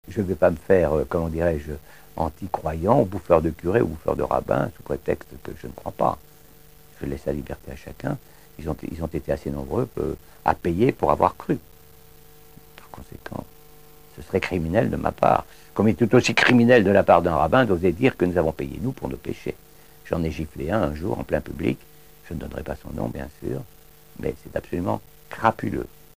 Témoignages de survivants.